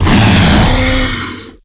attack_hit_2.ogg